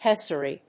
音節pes・sa・ry 発音記号・読み方
/pésəri(米国英語)/